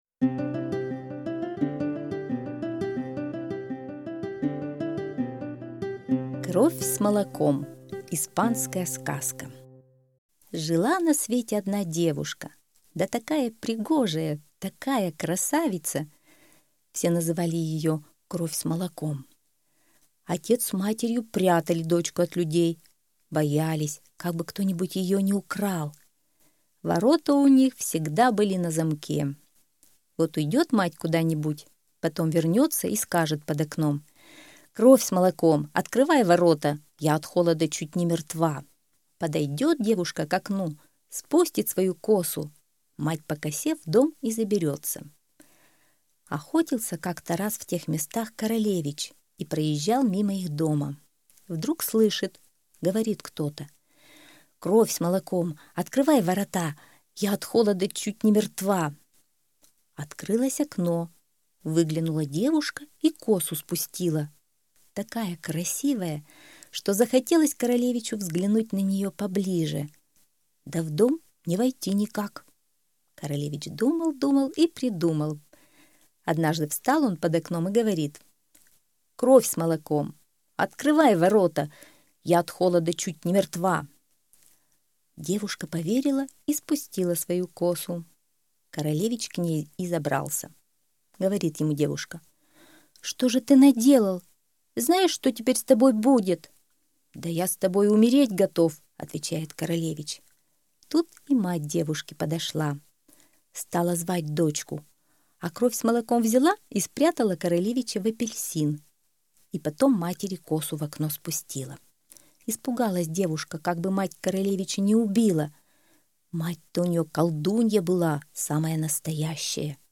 Кровь с молоком - испанская аудиосказка - слушать онлайн